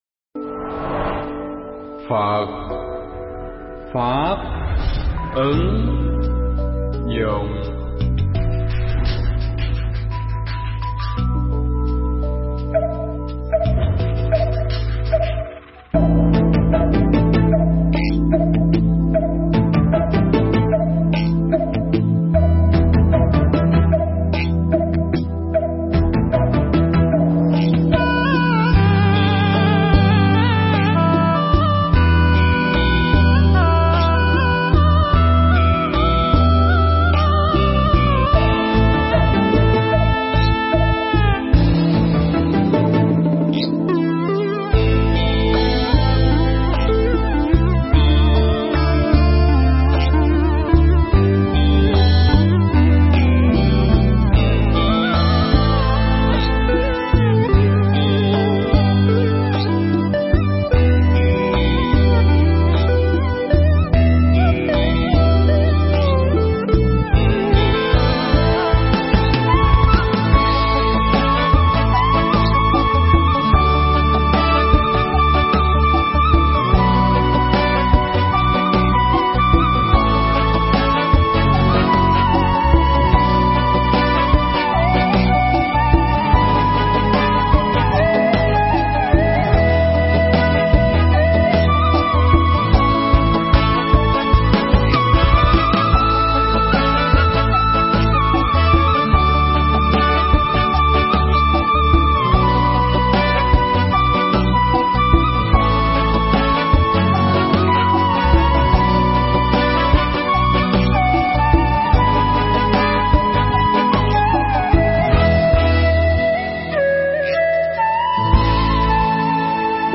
Mp3 Thuyết Pháp Sống Vội (KT66)
giảng trong khóa tu Một Ngày An Lạc lần thứ 66 tại Tu Viện Tường Vân